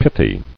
[pith·y]